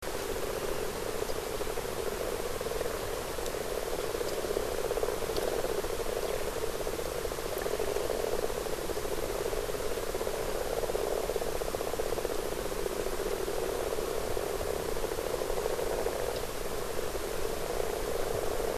amphibium_4.mp3